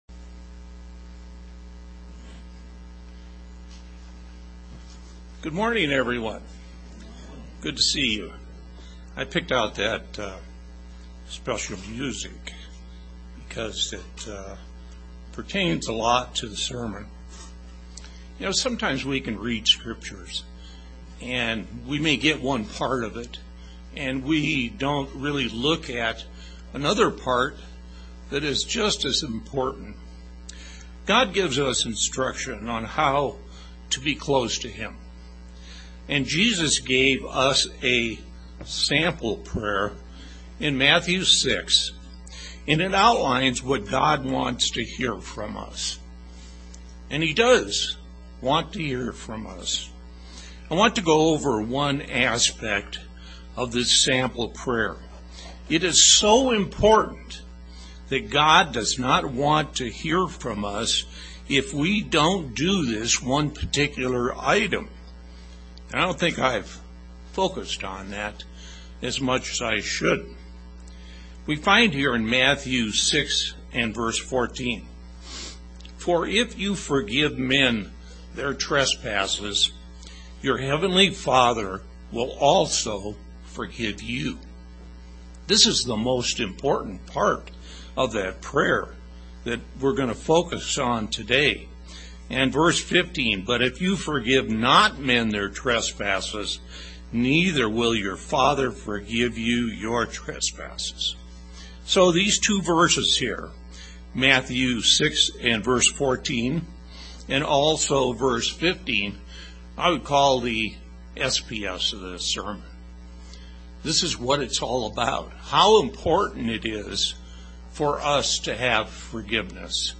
Forgiveness is an essential component to our salvation. Yes, God forgives us, but we are required to forgive those who trespass against us in order to receive this gift. This sermon emphasizes this concept through the scriptures.